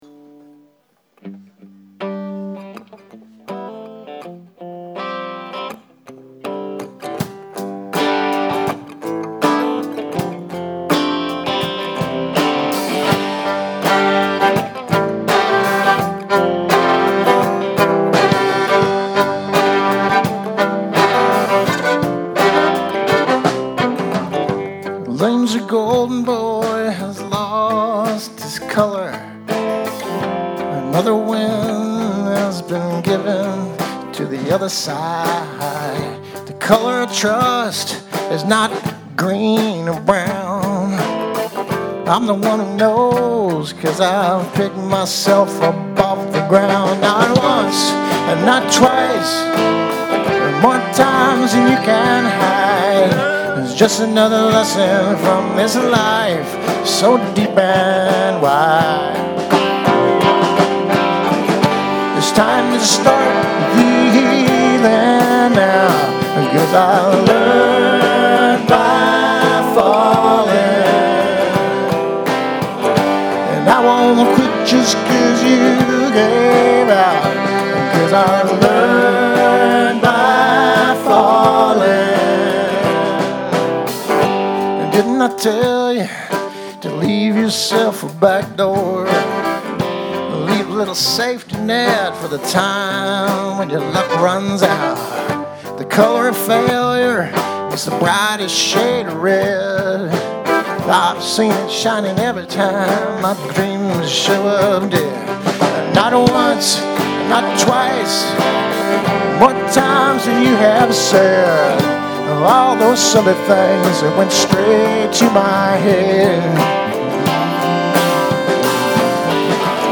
Can you tell he wants to rock and roll?